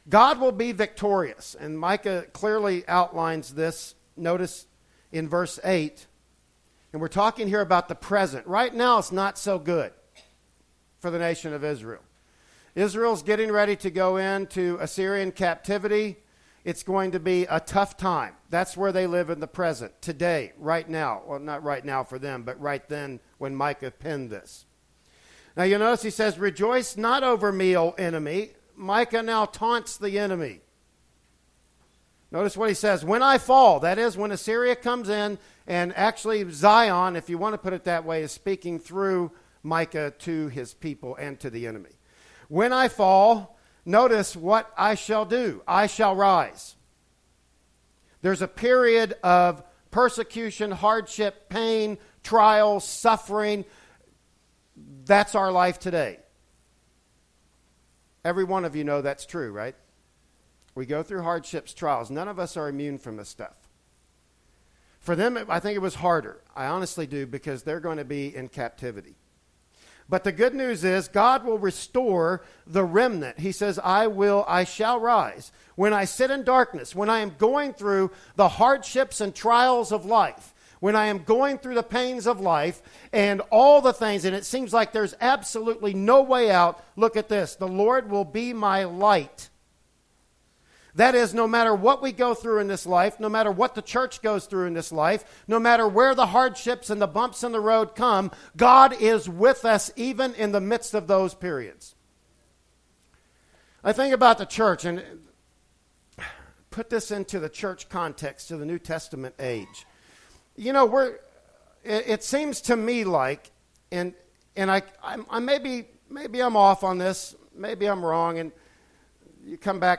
"Micah 7:8-20" Service Type: Sunday Morning Worship Service Bible Text